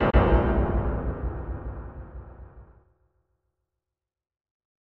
Sound effect from Super Mario RPG: Legend of the Seven Stars